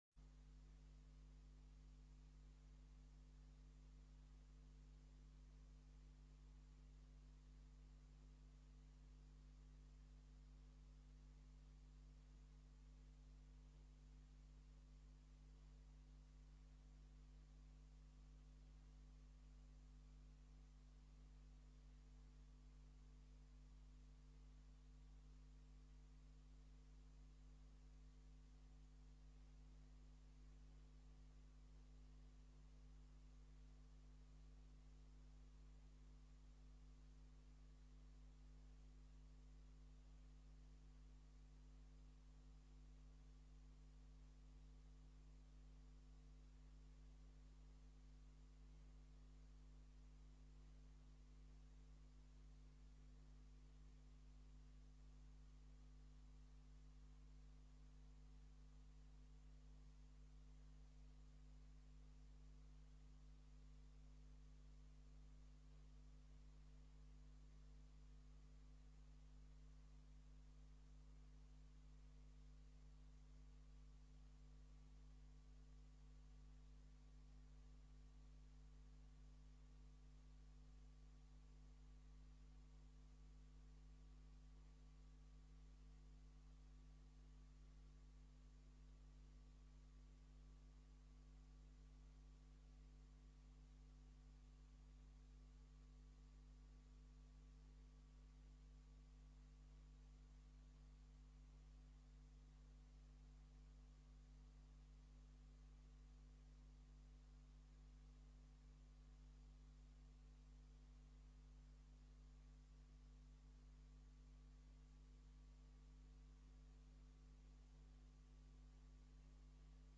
Informatiebijeenkomst 18 januari 2011 19:00:00, Gemeente Tynaarlo
Locatie: Raadszaal